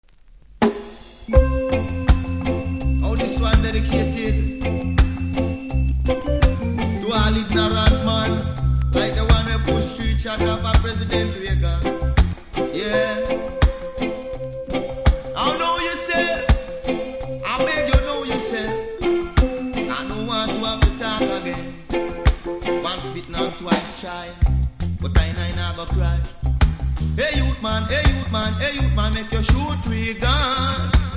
うすキズ多めですが音は良好なので試聴で確認下さい。